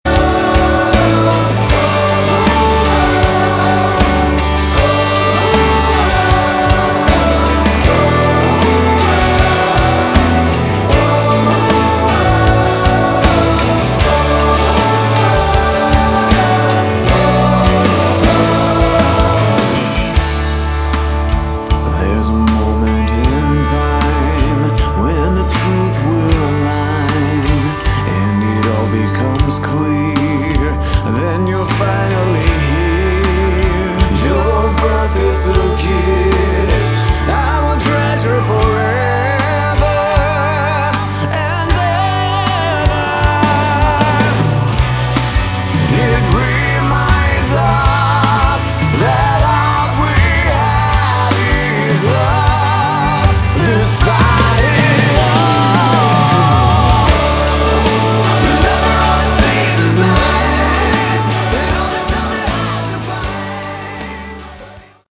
Category: Melodic Rock / AOR
guitar, bass, synthesizer, backing vocals
drums, percussion
keyboards